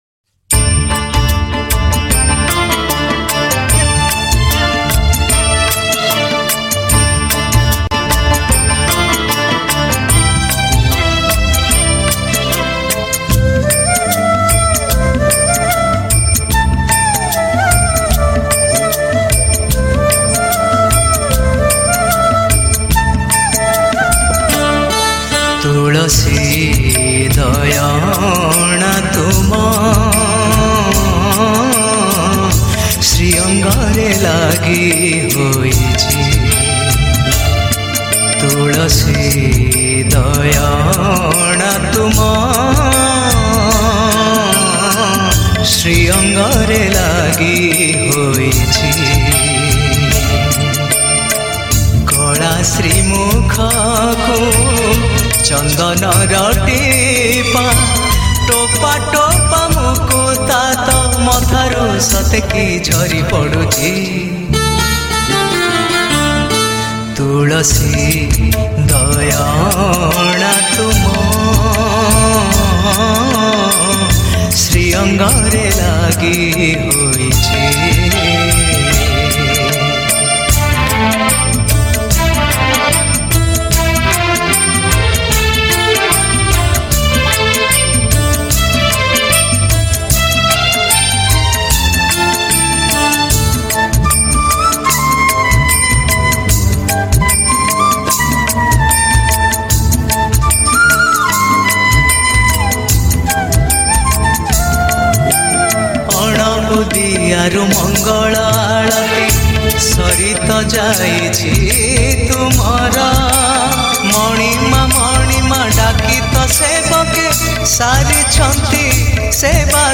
Odia Bhajan Song